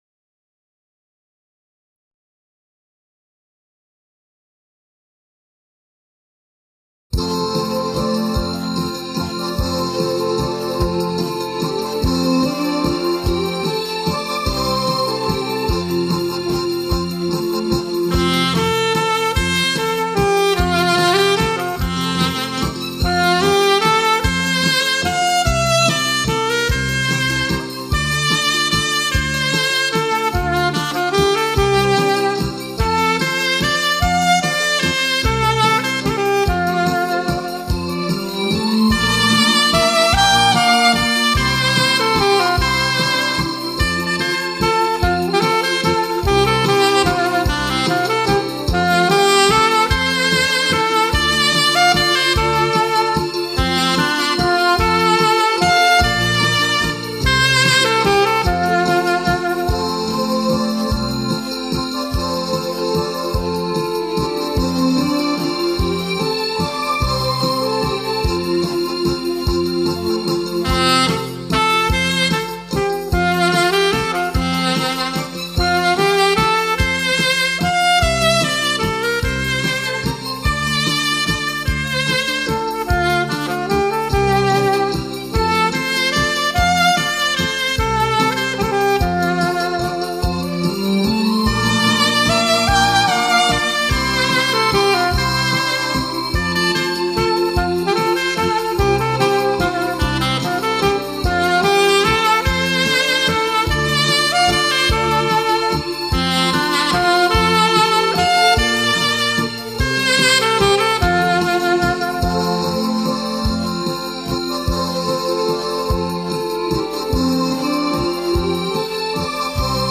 对中国民族音乐的精心诠释。